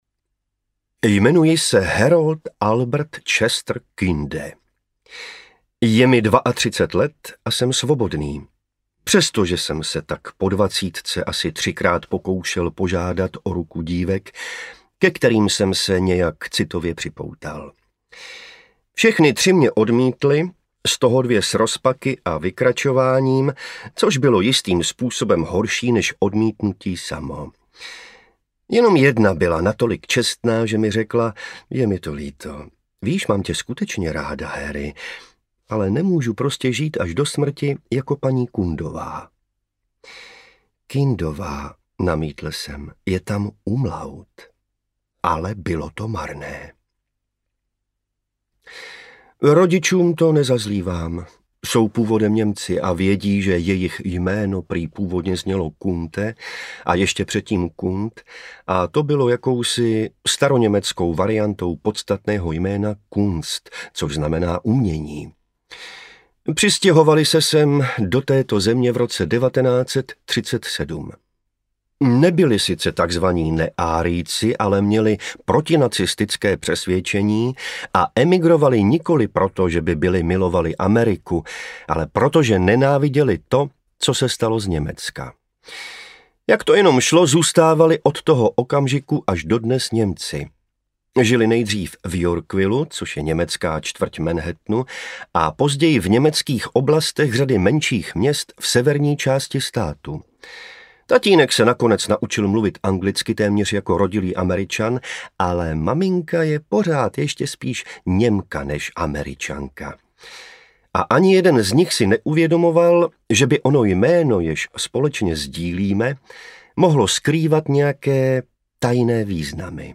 Jak nevyloupit banku audiokniha
Ukázka z knihy
| Vyrobilo studio Soundguru.